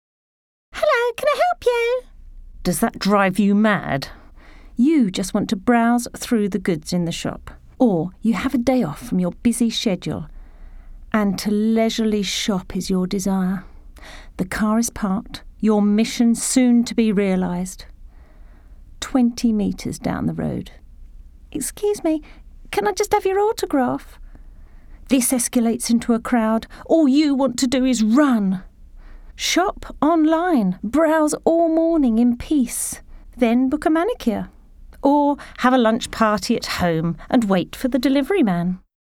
Voice Over
30's/40's Natural (.wav - 6.5Mb)